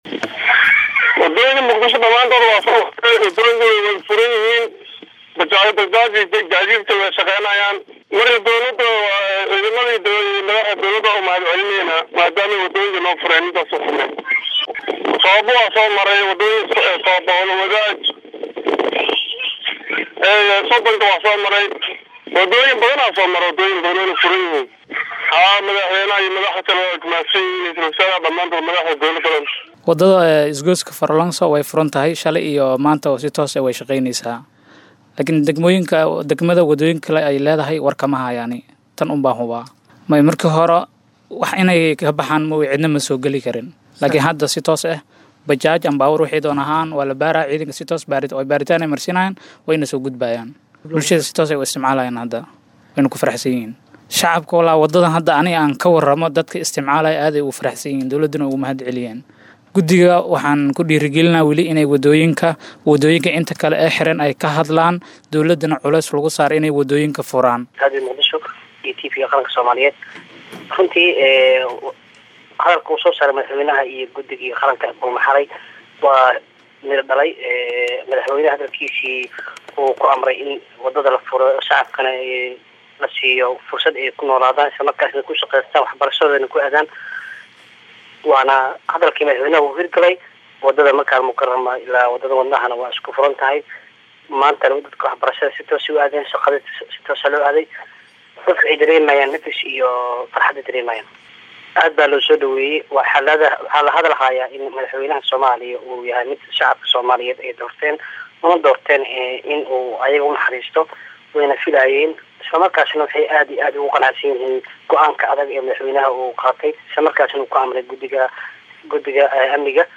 Qaar kamid ah shacabka magaalada Muqdisho, oo la hadlay Radio Muqdisho, ayaa sheegay in waddooyinka dib loo furey isla markaana shaqooyinka ay si caadi ah u socdaan.